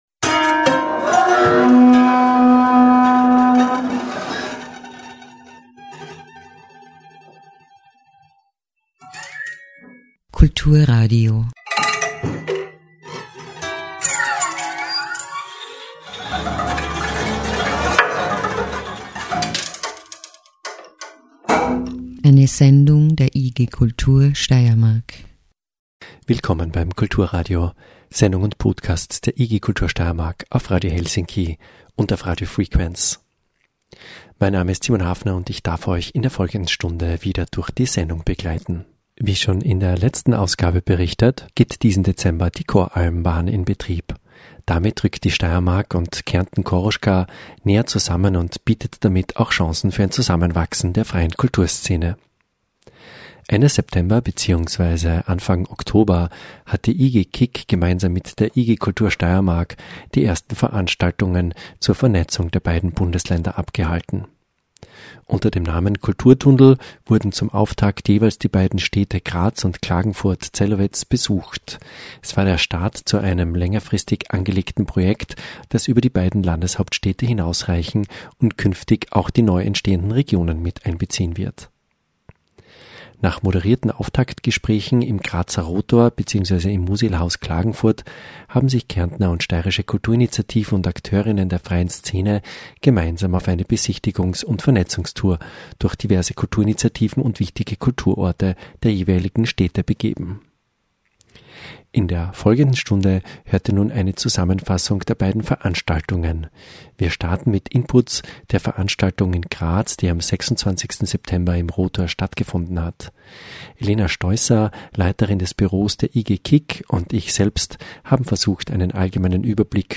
In der Oktober Ausgabe des Kulturradios hört ihr Mitschnitte von Diskussionen im Rahmen der ersten Kulturtunnel Veranstaltungen in Klagenfurt / Celovec und Graz.